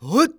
xys蓄力8.wav 0:00.00 0:00.40 xys蓄力8.wav WAV · 34 KB · 單聲道 (1ch) 下载文件 本站所有音效均采用 CC0 授权 ，可免费用于商业与个人项目，无需署名。